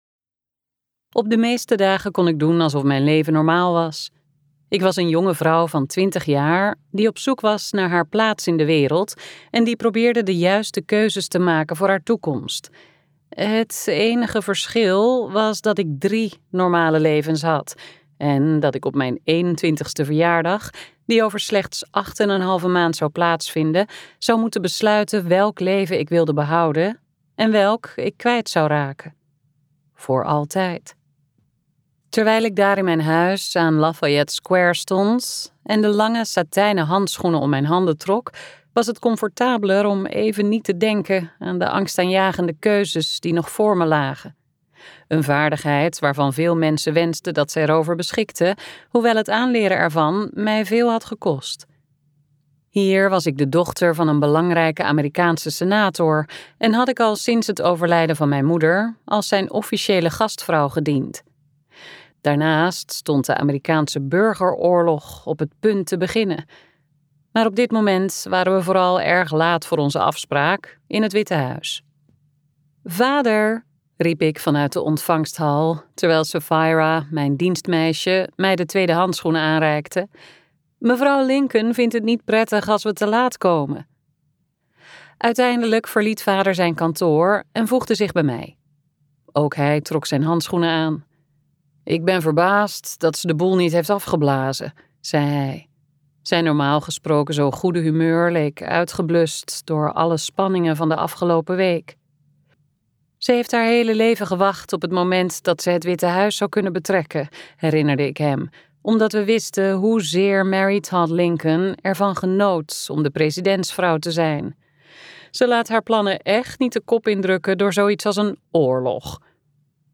KokBoekencentrum | Voordat ik loslaat luisterboek